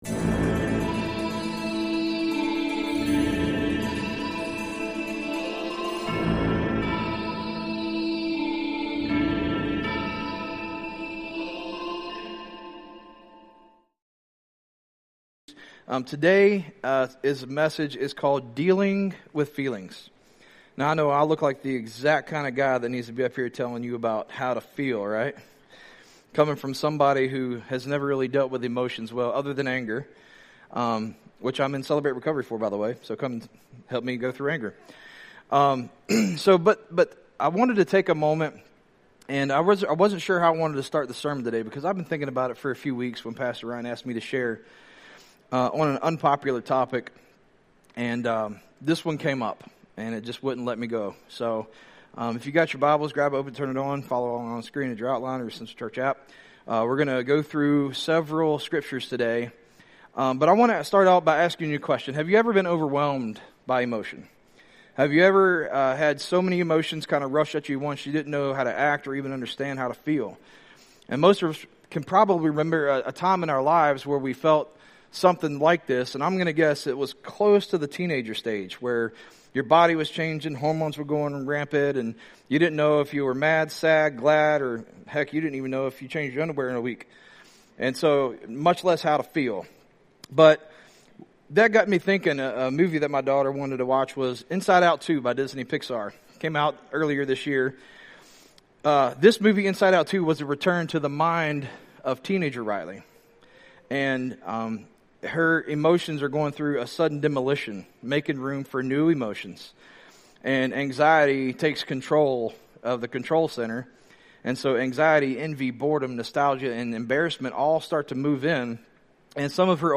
We're in week 4 of our sermon series - Unpopular - with the message, "Dealing with Feelings" - inspired by 2 Corinthians 10, along with several other verses.